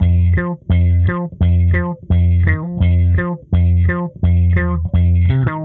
Index of /musicradar/dusty-funk-samples/Bass/85bpm